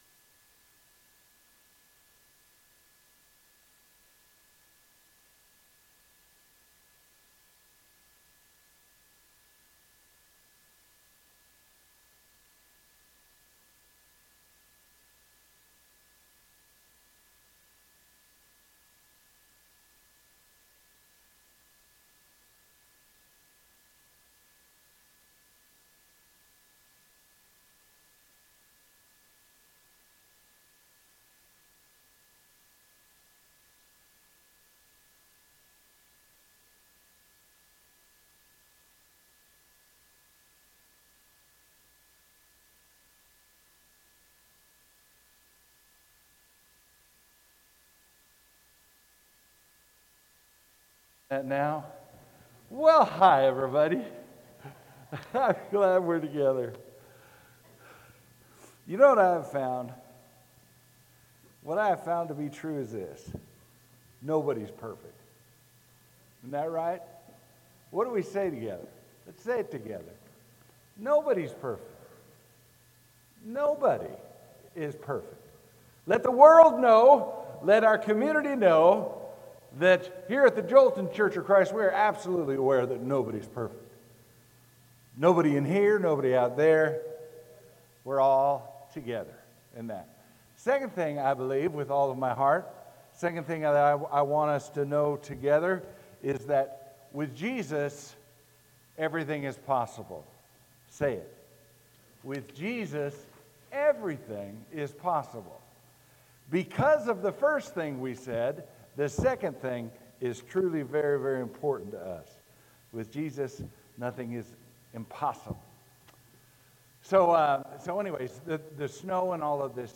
SERMON: For God So Loved the World – IGNITE-TRANSFORM-REFLECT-SHINE